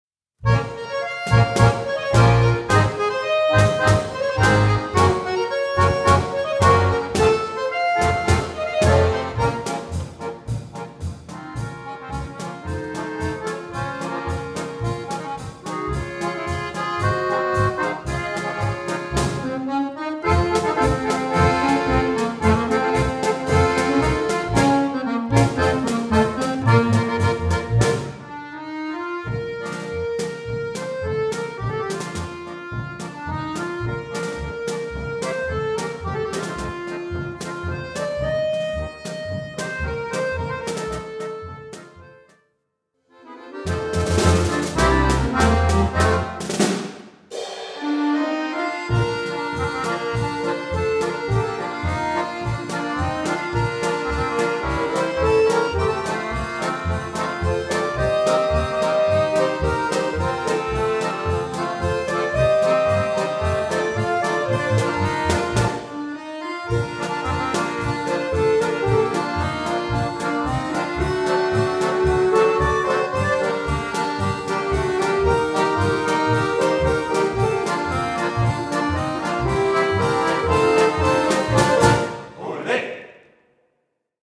Orchestre d'Accordéons